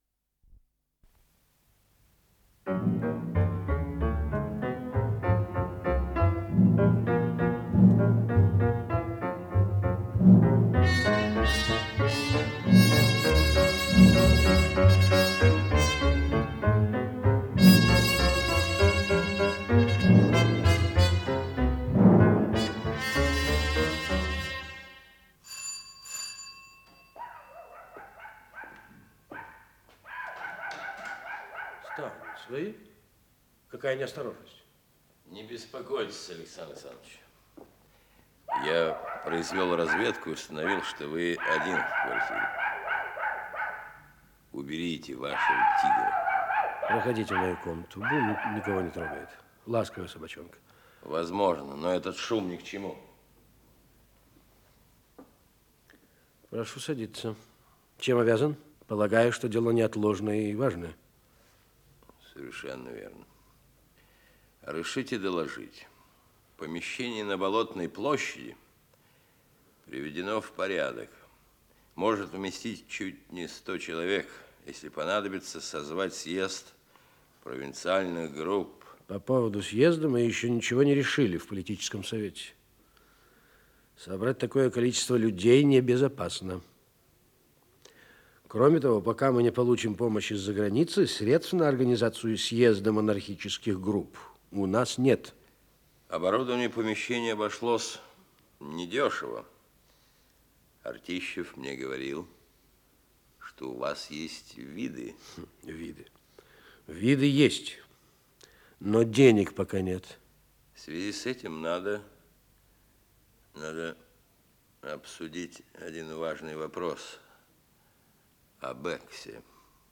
Исполнитель: Артисты московских театров
Радиопостановка